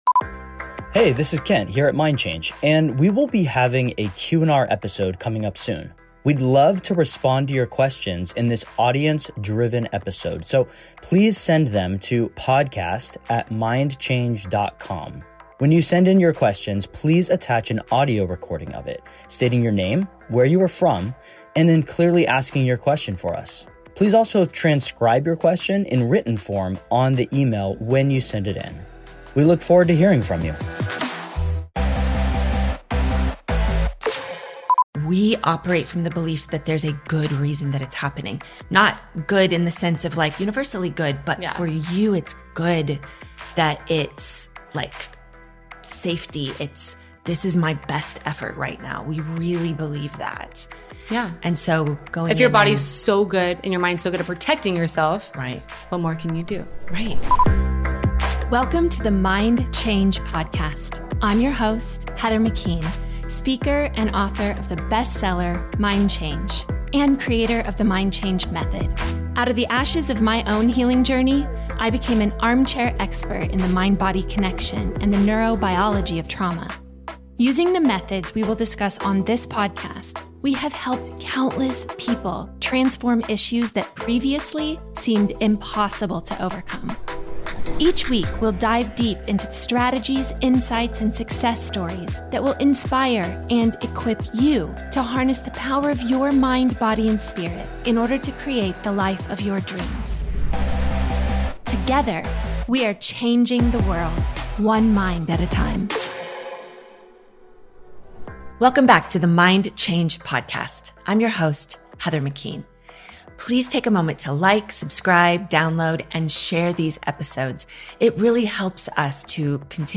This heartfelt conversation touches on birth trauma, breaking subconscious programs, and the ripple effect of healing across generations.